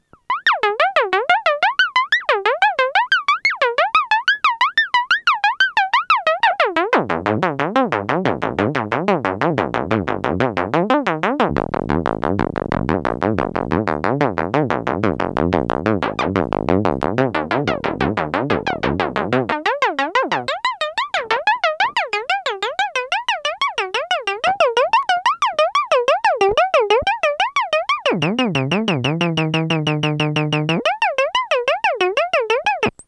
Arp Odyssey
Sample and hold mix